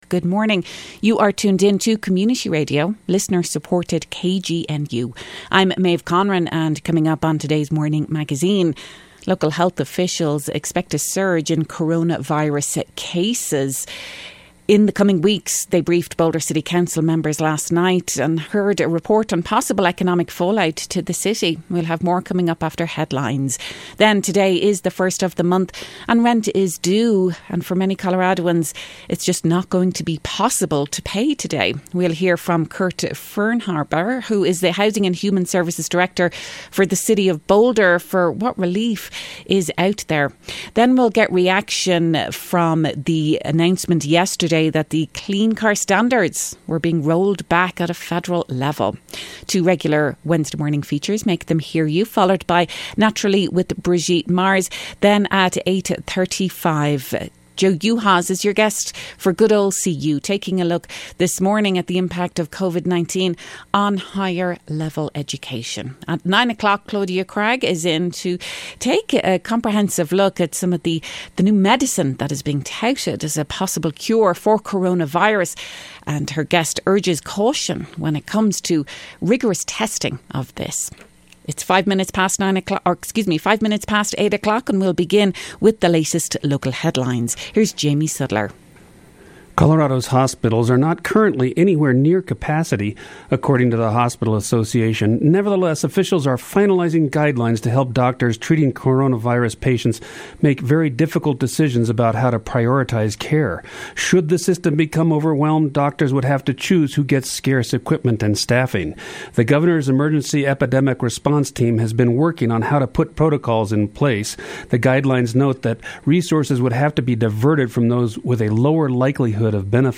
The Morning Magazine features local news headlines, stories and features and broadcasts on KGNU Monday through Friday 8.04-8.30am.